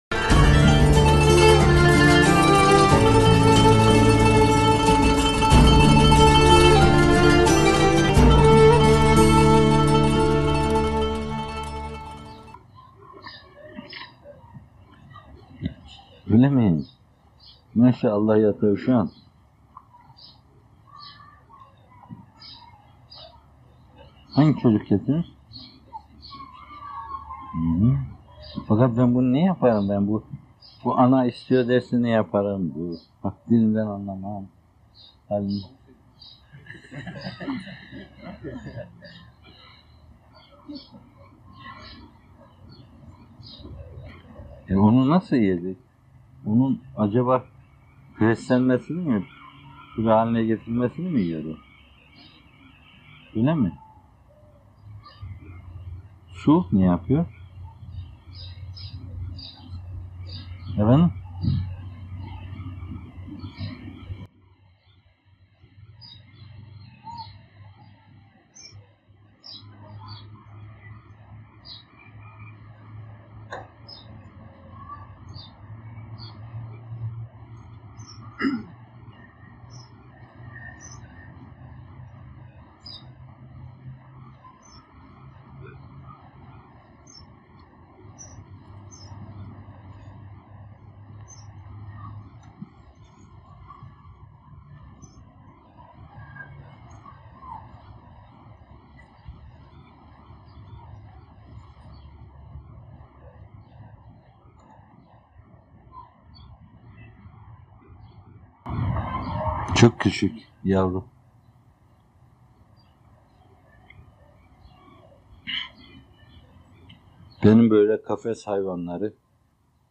Tavşan, Tabiat ve Şefkat Mülahazaları - Fethullah Gülen Hocaefendi'nin Sohbetleri